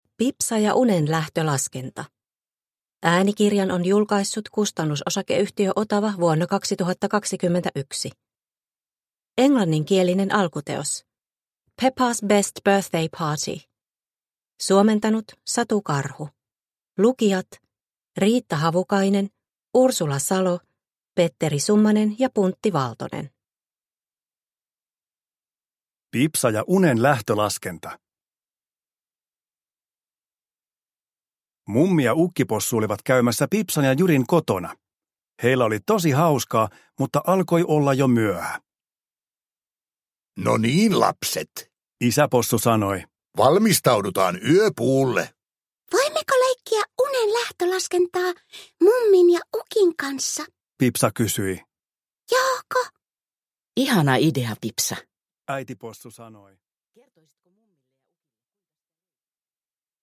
Pipsa ja unen lähtölaskenta – Ljudbok – Laddas ner